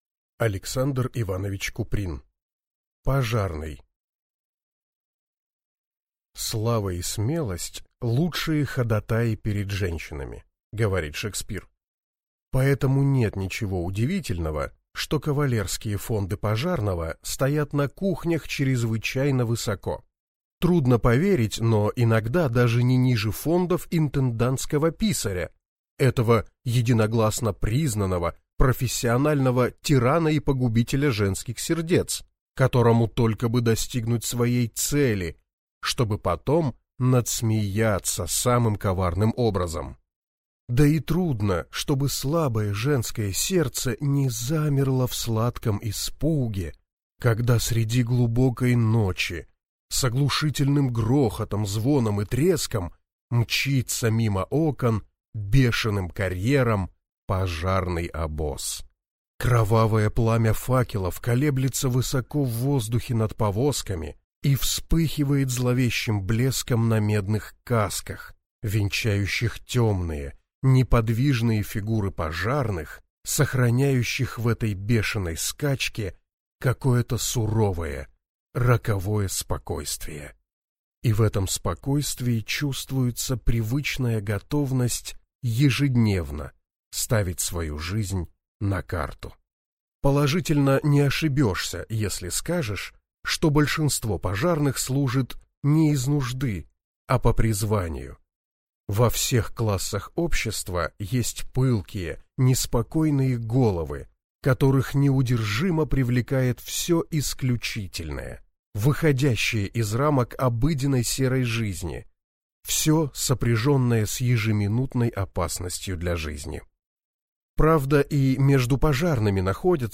Аудиокнига Пожарный | Библиотека аудиокниг
Прослушать и бесплатно скачать фрагмент аудиокниги